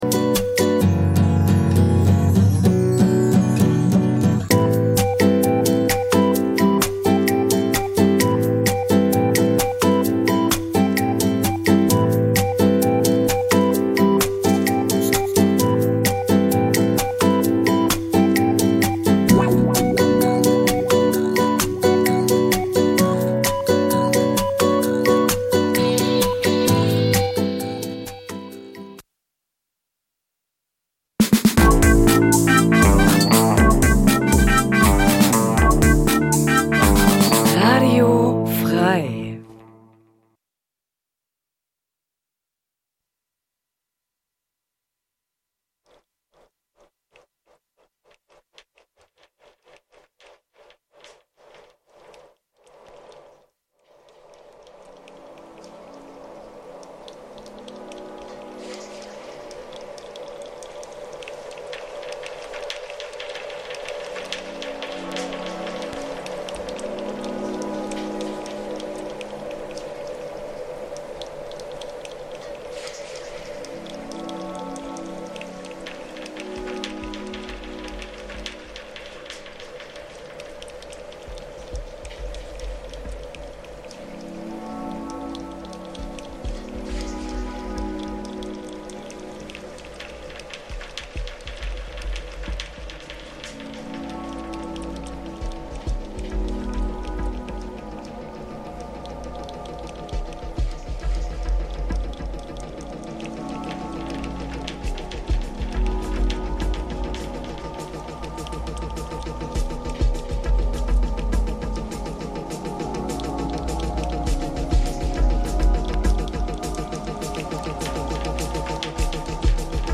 Mixe